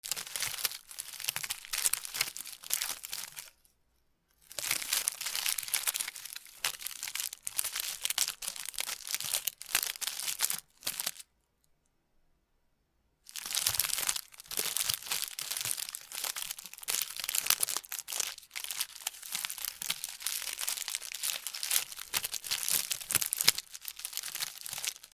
ビニールの包みのお菓子
『カシャカシャ』